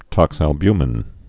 (tŏksăl-bymĭn)